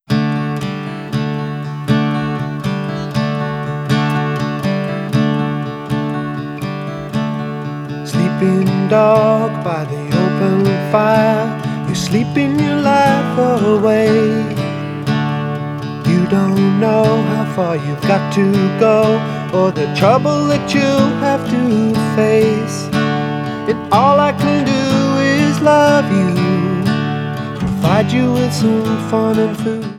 The 2006 CDs sound a bit louder.